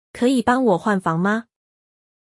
Kěyǐ bāng wǒ huàn fáng ma?